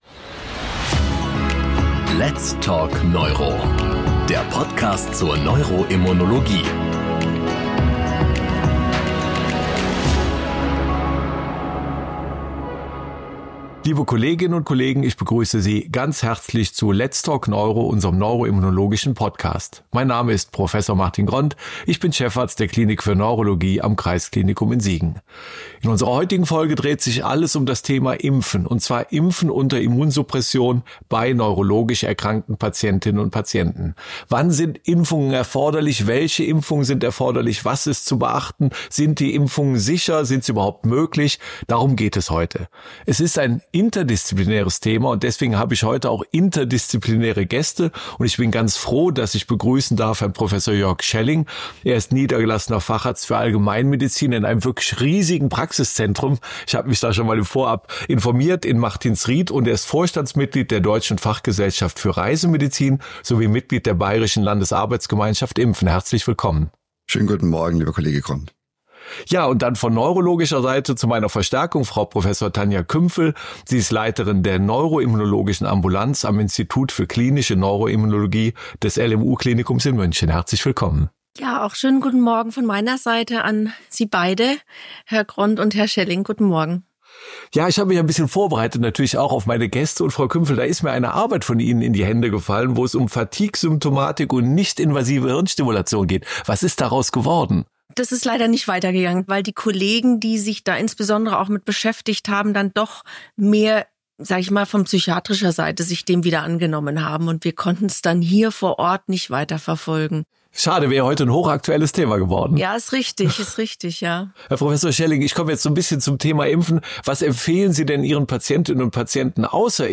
Dieses interdisziplinäre Thema wird von zwei Gästen diskutiert